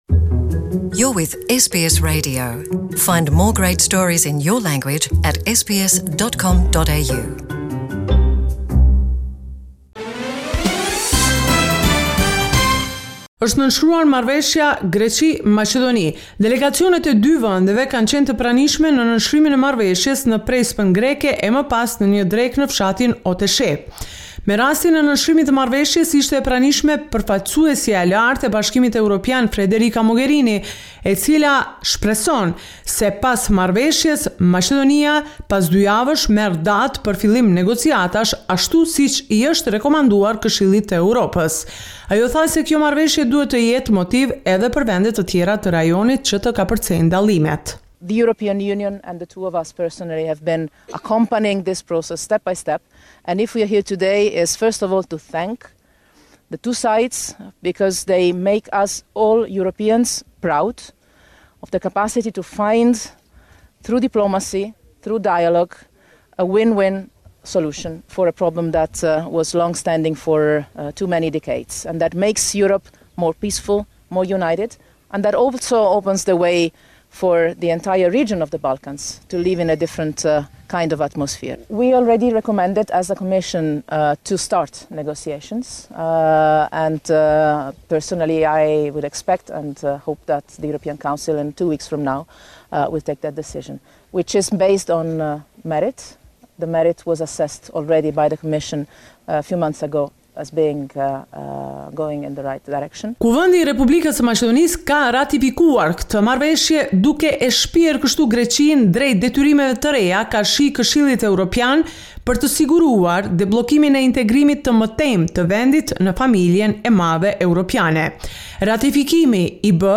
The weekly report with the latest developments in Macedonia.